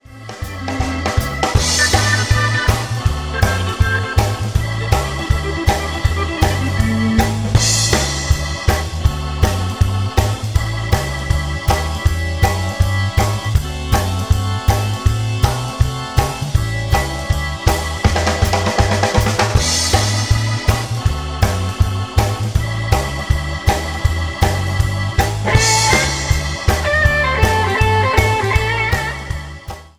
BLUES  (03.30)